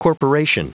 Prononciation du mot corporation en anglais (fichier audio)
Prononciation du mot : corporation